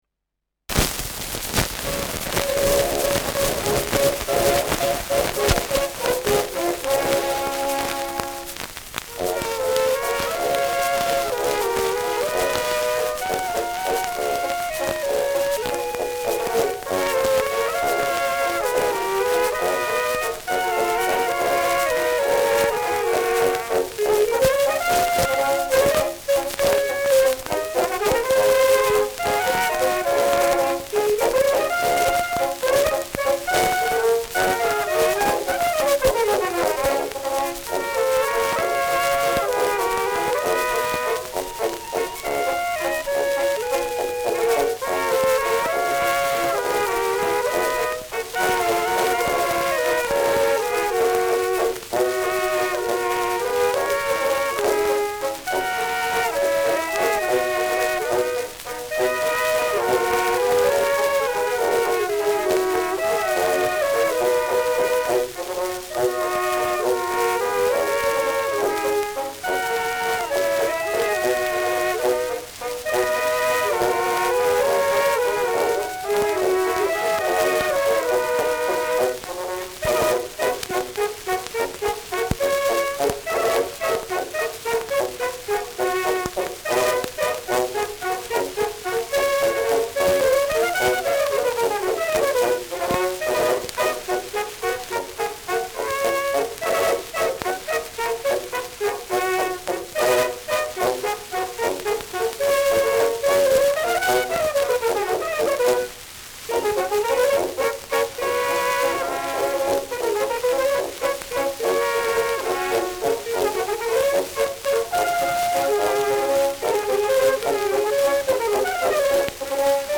Schellackplatte
Tonnadel „rutscht“ zu Beginn über einige Tonrillen : leiert : starkes Rauschen zu Beginn : Nadelgeräusch : gelegentlich Knacken
Truderinger, Salzburg (Interpretation)
[Salzburg] (Aufnahmeort)